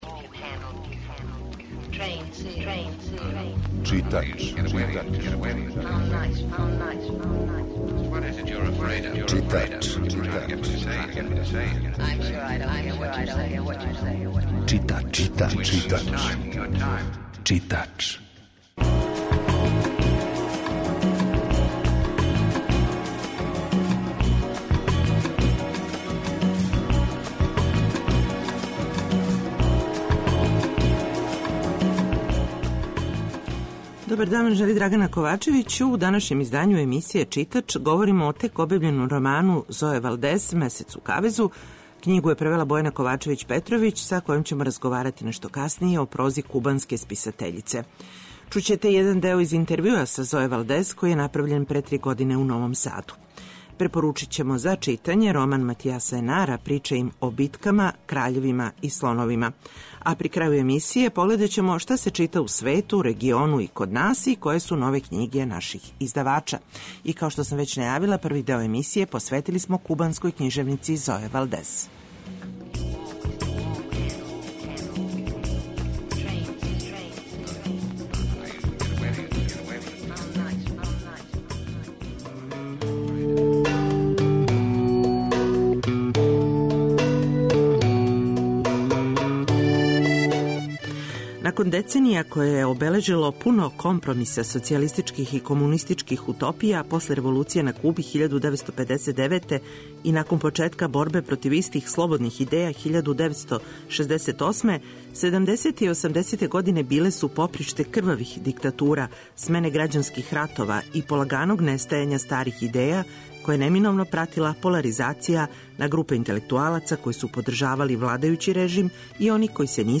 Чућете и део разговора који је са Сое Валдес вођен пре три године у Новом Саду.
Емисија је колажног типа